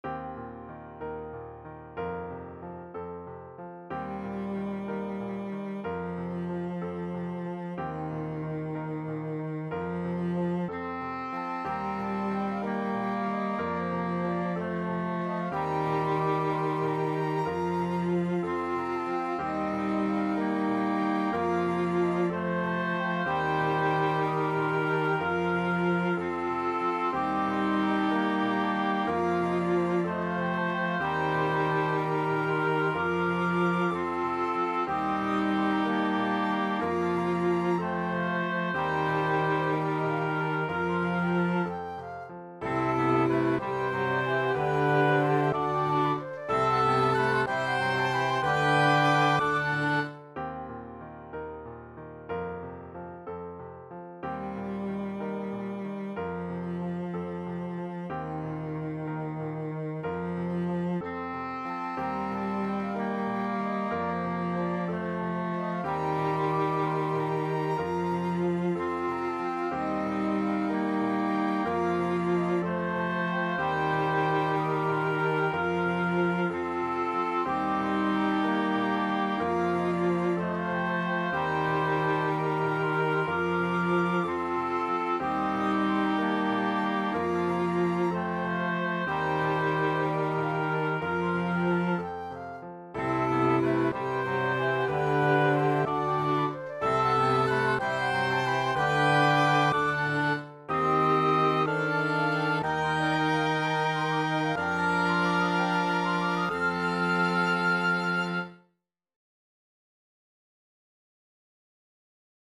Voicing/Instrumentation: SATB
Flute Piano